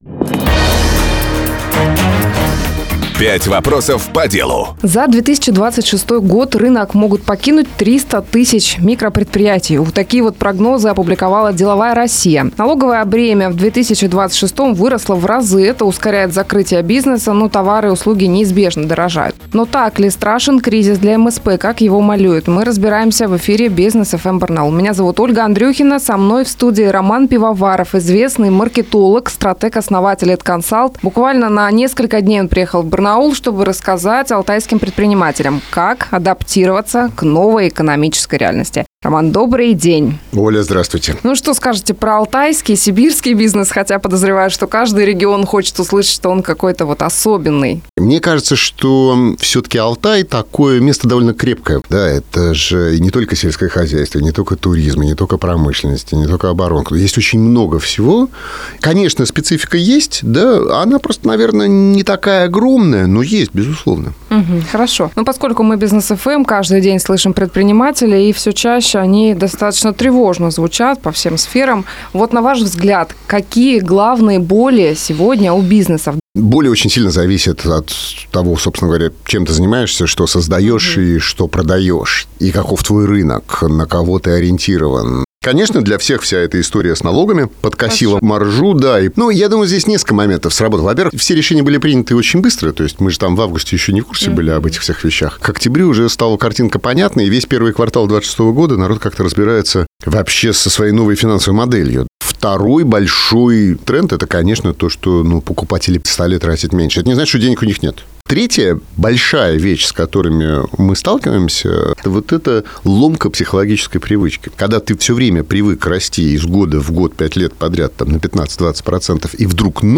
Известный маркетолог и стратег побывал в гостях Business FM Барнаул